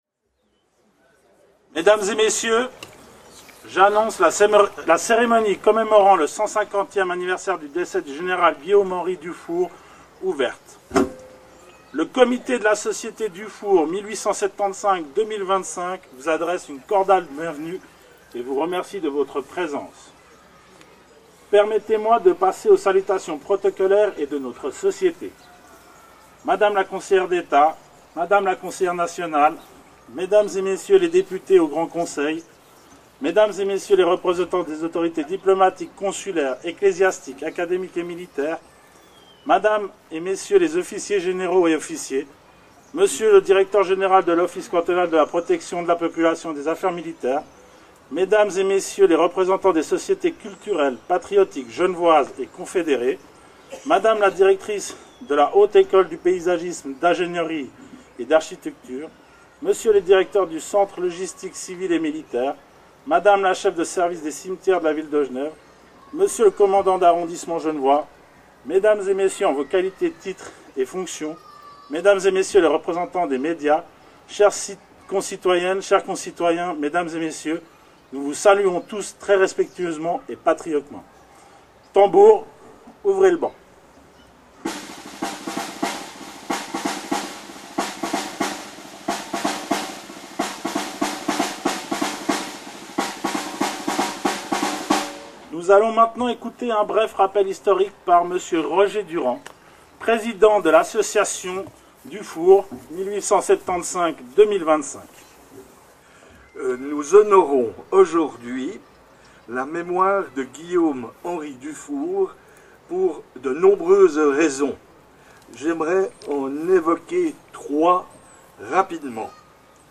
PODCAST et allocutions
au cimetière des Rois